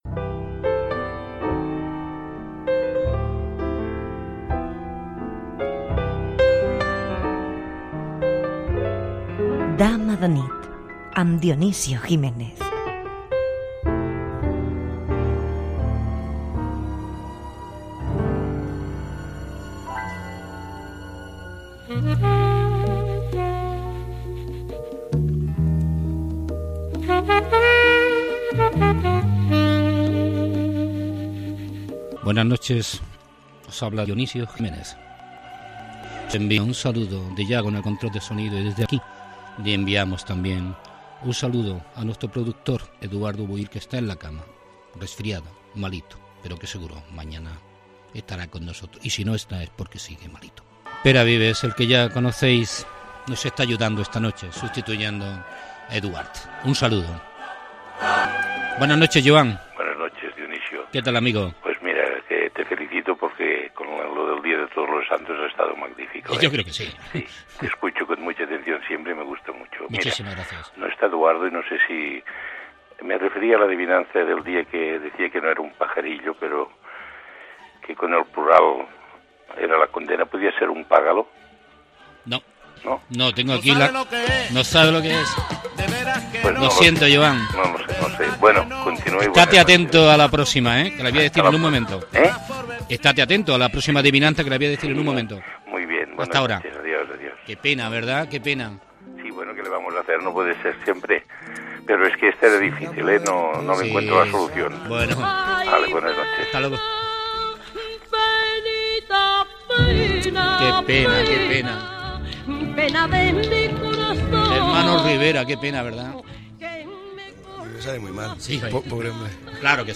Careta i inici del programa, amb els integrants de l'equip.
Entreteniment